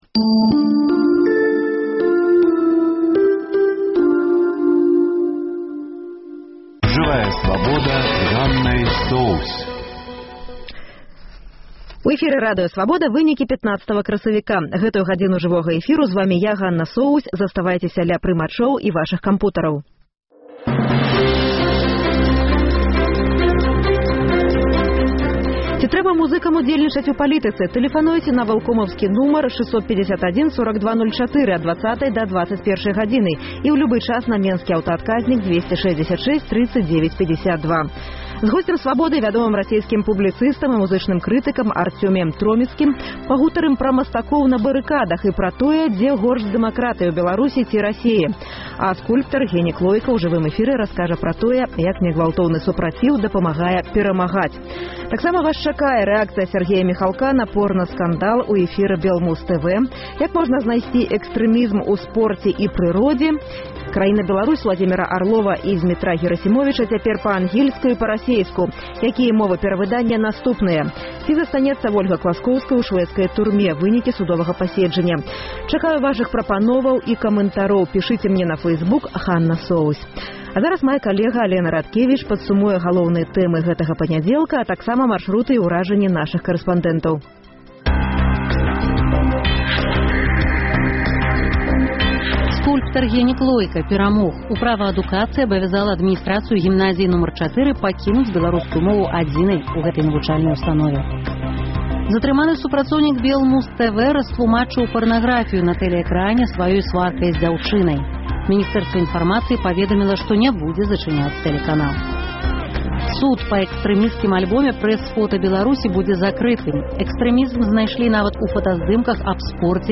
З госьцем «Свабоды» вядомым расейскім публіцыстам і музычным крытыкам Арцёміем Троіцкім пагаворым пра масткоў на барыкадах, беларускіх музыкаў у Расеі і пра тое, дзе горш з дэмакратыяй — у Беларусі ці Расеі.